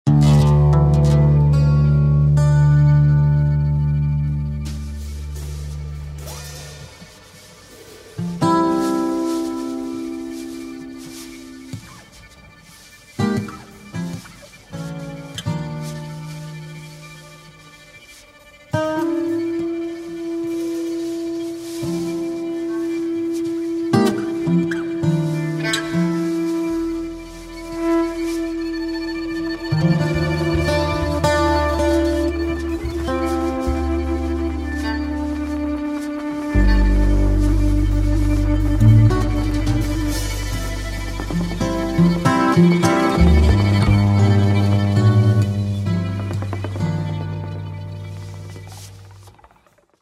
Aufgenommen am 12.12.04 im Porgy & Bess Wien